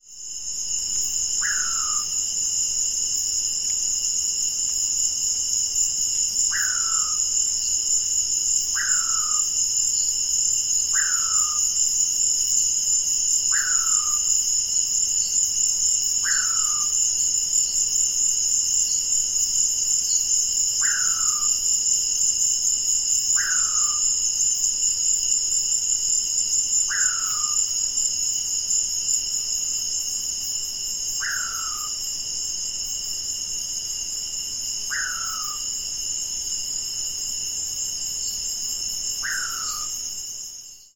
Atajacaminos Ocelado (Nyctiphrynus ocellatus)
Nombre en inglés: Ocellated Poorwill
Condición: Silvestre
Certeza: Observada, Vocalización Grabada